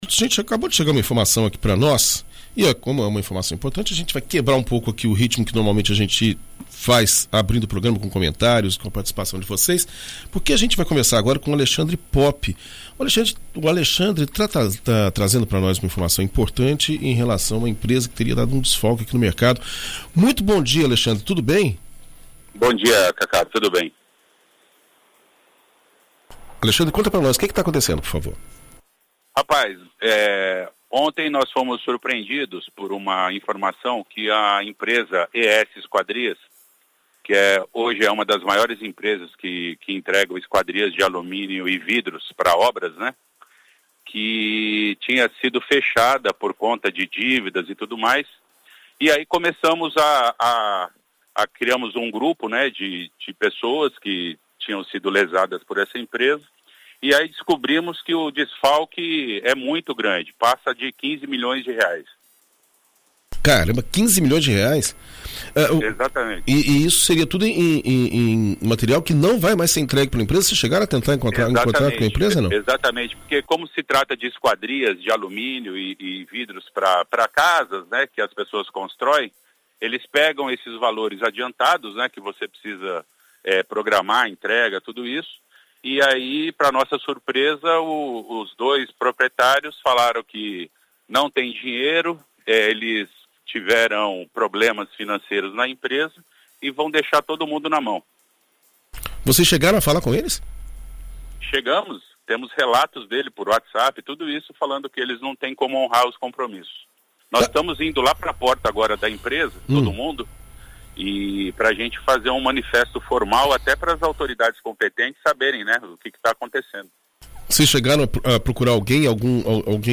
Em entrevista à BandNews FM Espírito Santo nesta terça-feira